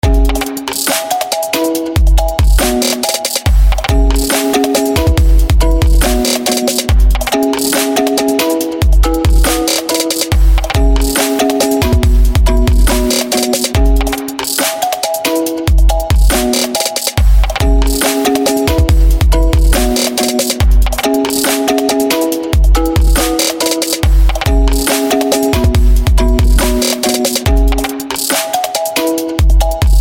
Category: Electronic Ringtones Tags